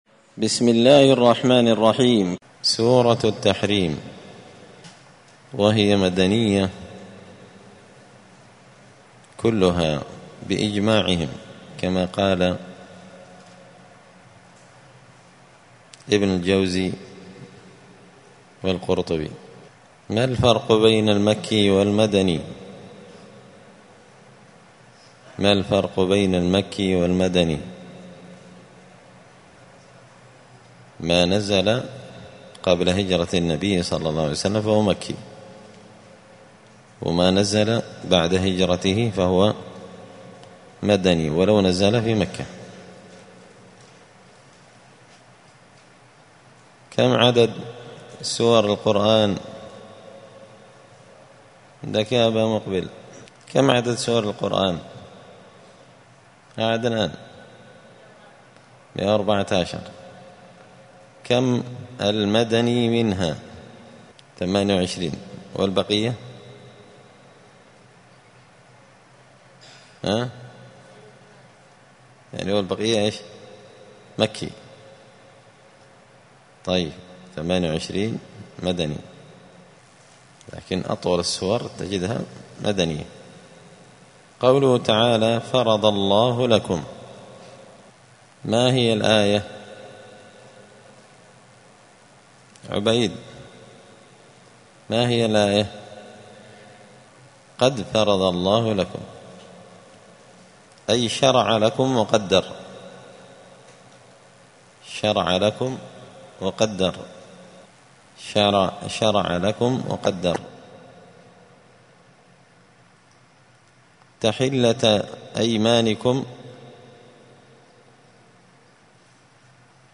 الأربعاء 3 رمضان 1445 هــــ | الدروس، دروس القران وعلومة، زبدة الأقوال في غريب كلام المتعال | شارك بتعليقك | 23 المشاهدات